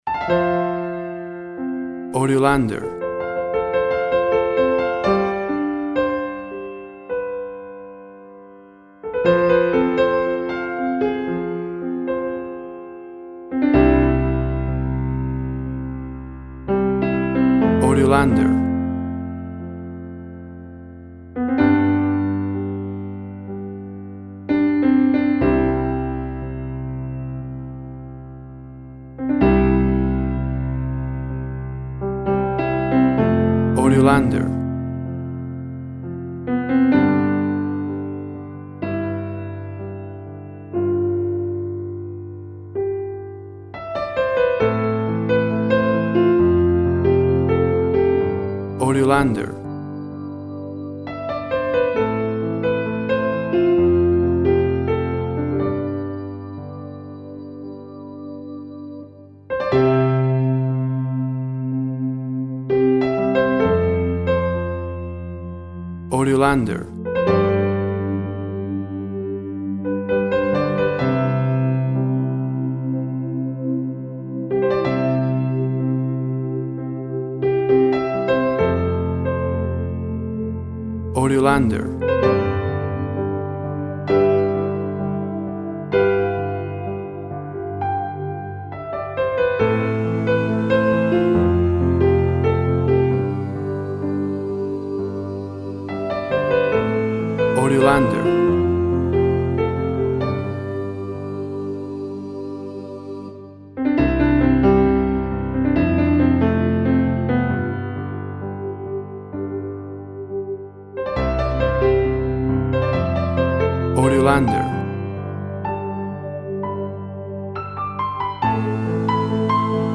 Touching & inspiring serene piano melody.
Tempo (BPM) 68